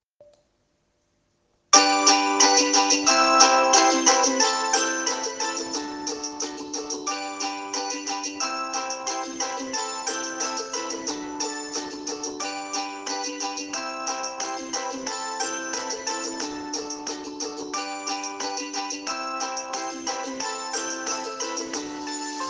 Attività 2 Campo di esperienza: Immagini, suoni, colori Musica da camera… anzi da cucina!